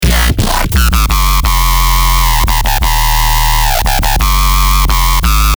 ZODIAC: DRUM & BASS
Bass Full 6 A#min
Antidote_Zodiac-Bass-Full-6-Amin.mp3